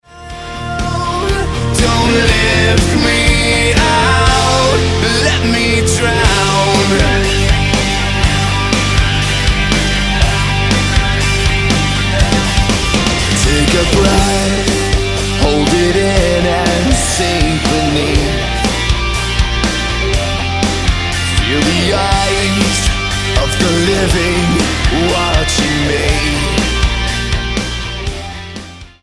alternative hard rock band
Hard Music